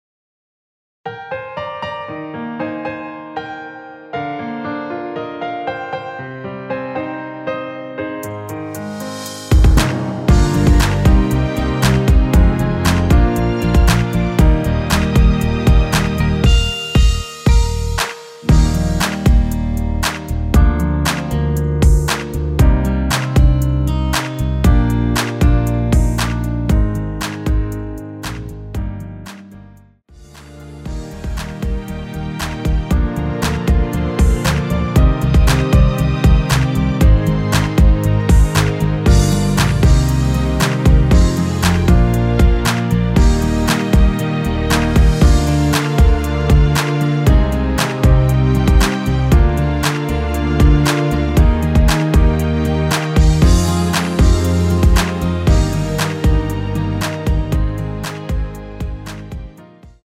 내린 MR
Eb
앞부분30초, 뒷부분30초씩 편집해서 올려 드리고 있습니다.
중간에 음이 끈어지고 다시 나오는 이유는
곡명 옆 (-1)은 반음 내림, (+1)은 반음 올림 입니다.